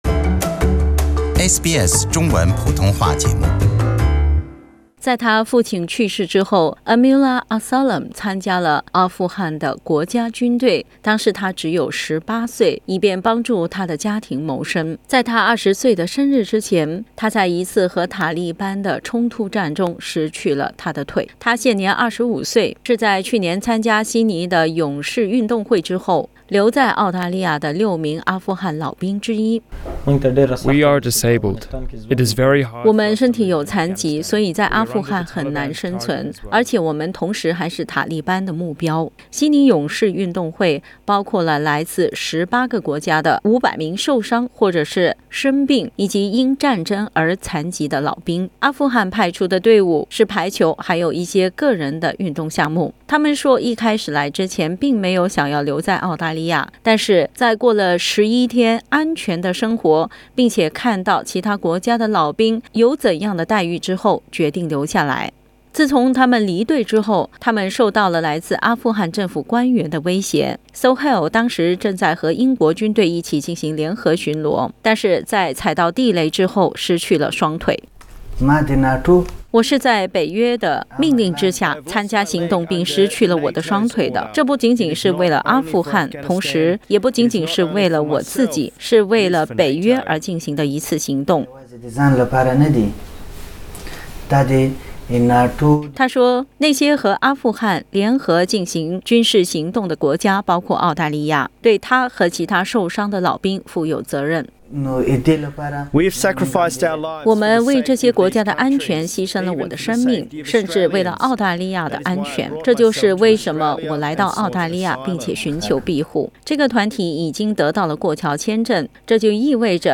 他们接受了澳大利亚的独家专访。